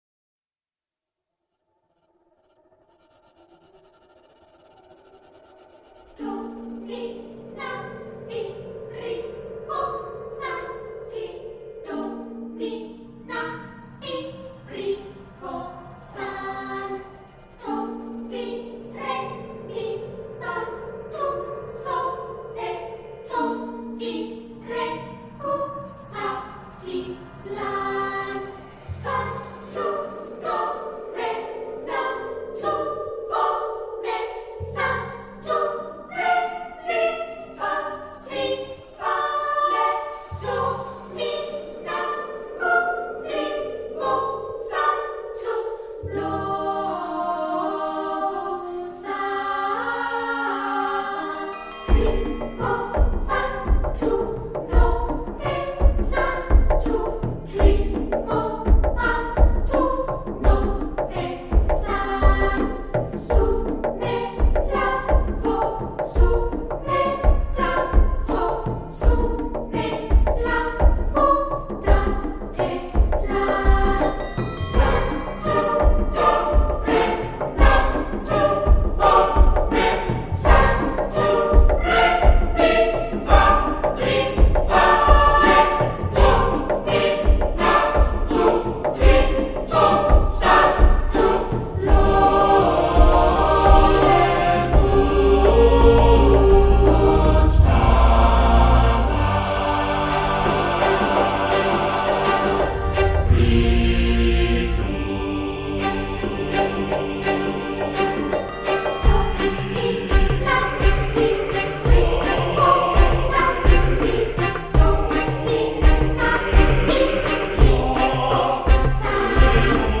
ses cordes syncopées et ses percussions sèches.